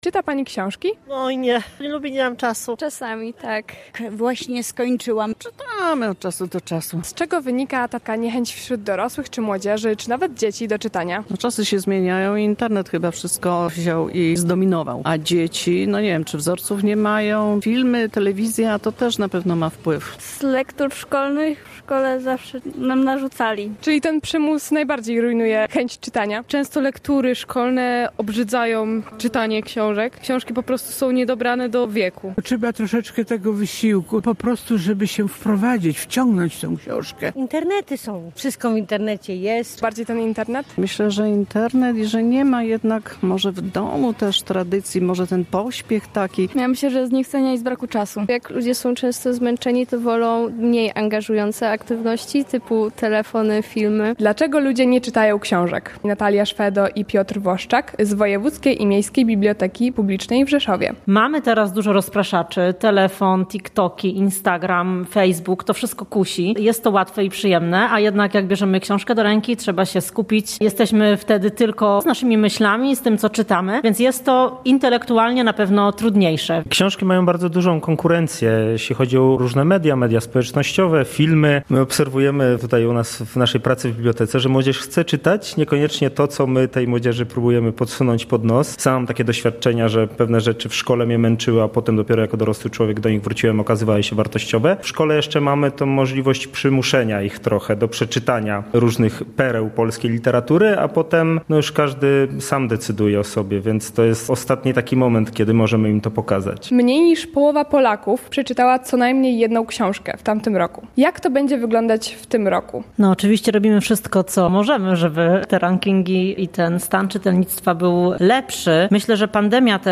Sonda: Czy mieszkańcy Rzeszowa często sięgają po książki?